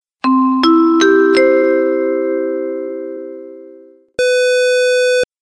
Startsignal.mp3